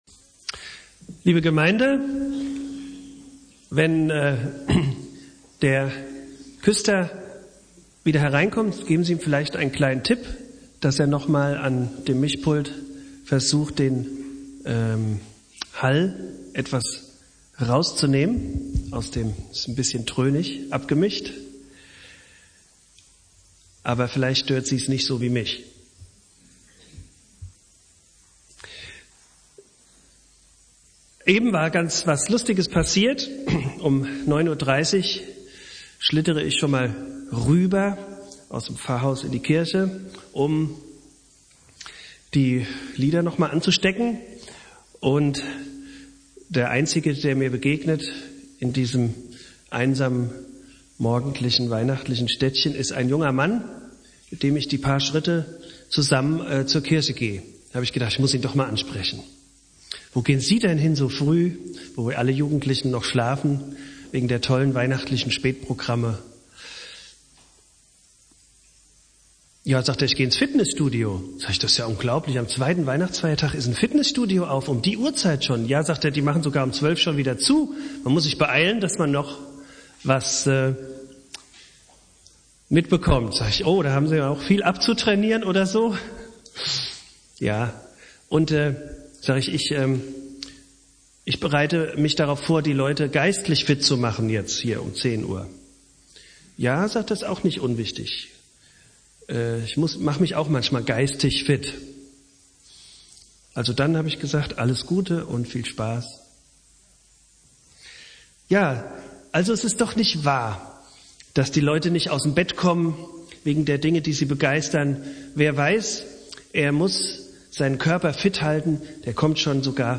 Predigt
2.Weihnachtstag